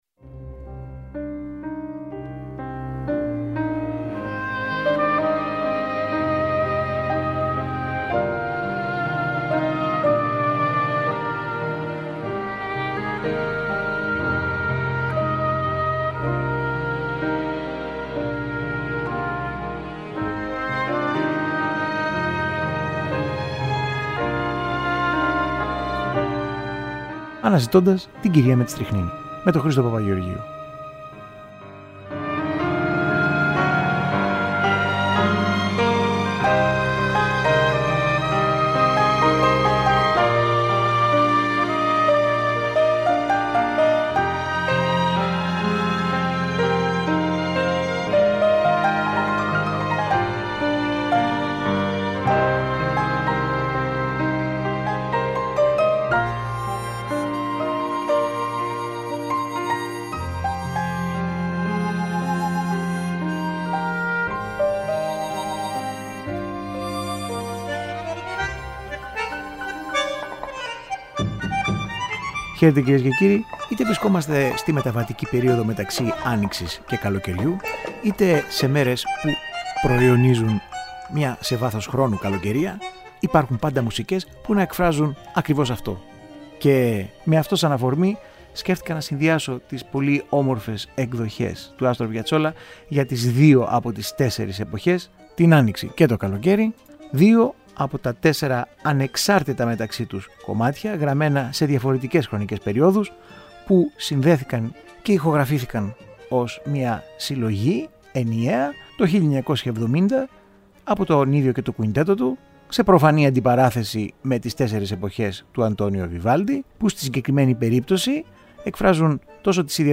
Στη μεταβατική αυτή στιγμή του χρόνου ακούμε ένα συνδυασμό από εξαιρετικές εκδοχές δύο εκ των “τεσσάρων εποχών” του περίφημου Αργεντίνου βιρτουόζου σε συγκριτικές ακροάσεις.